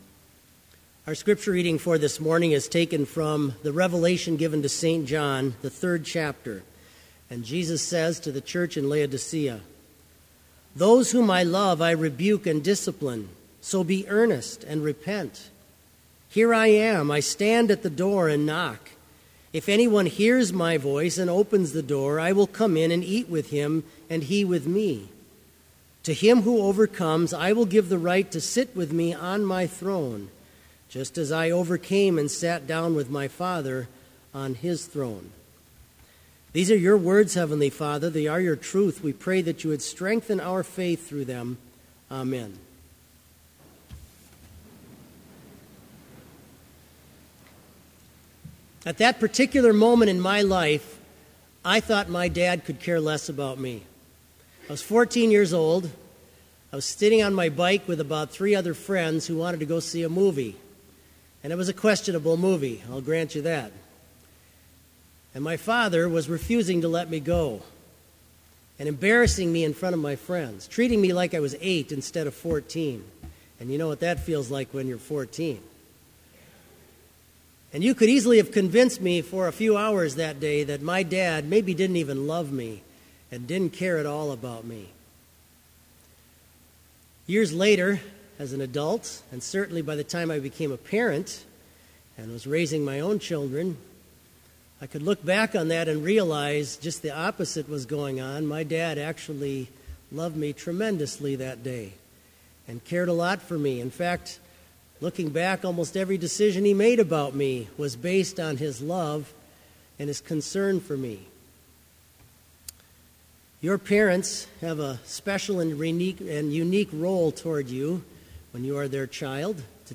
Complete Service
This Chapel Service was held in Trinity Chapel at Bethany Lutheran College on Friday, December 11, 2015, at 10 a.m. Page and hymn numbers are from the Evangelical Lutheran Hymnary.